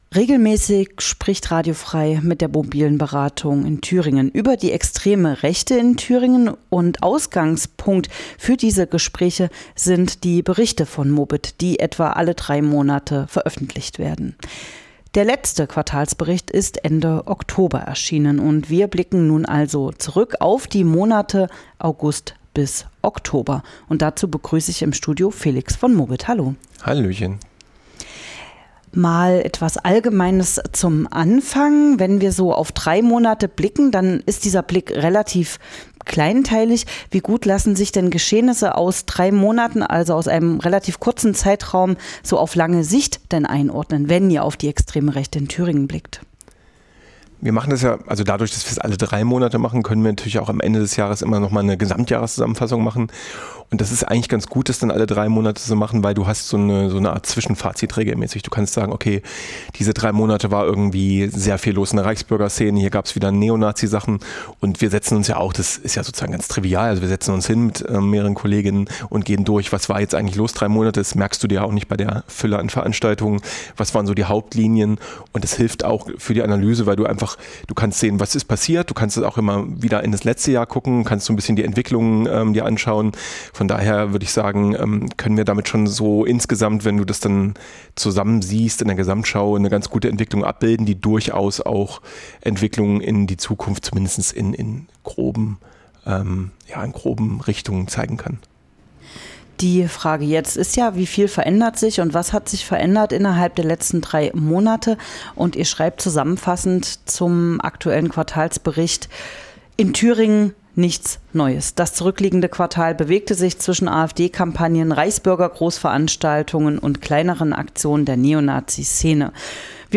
Im Blick - Dokumentation extrem rechter Aktivitäten in Thüringen 03/2025 | Interview mit Mobit